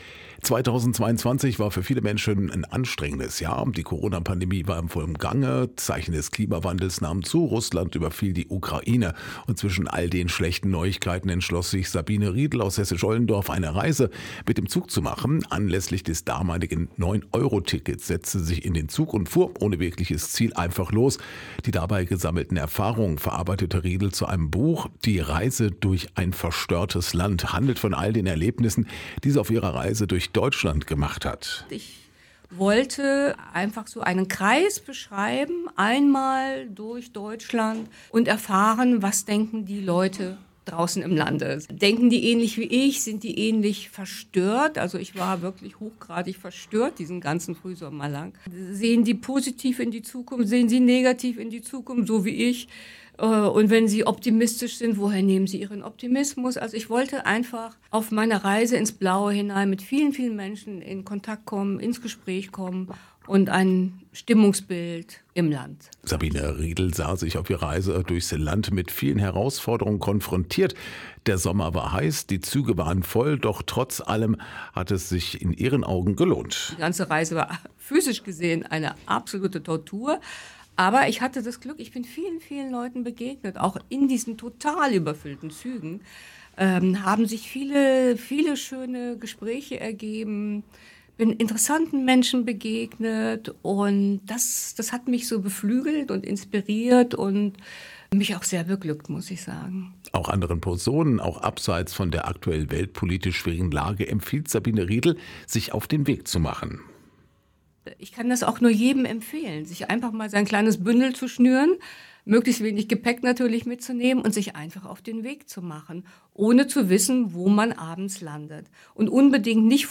Aktuelle Lokalbeiträge